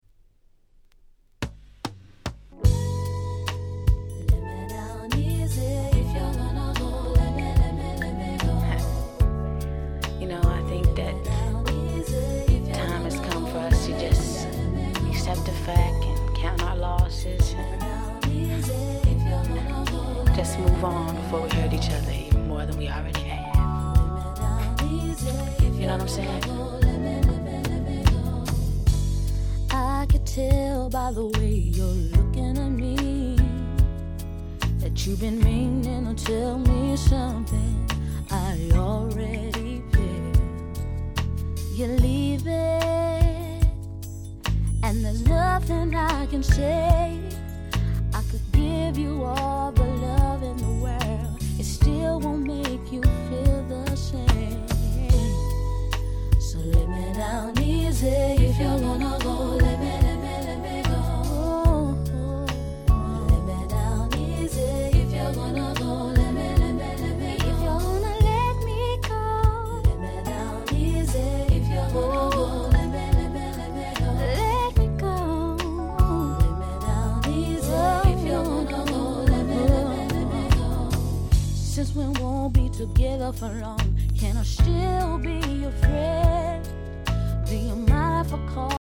98' Nice R&B LP !!
統一したNeo Soul感が素晴らしい名盤中の名盤です！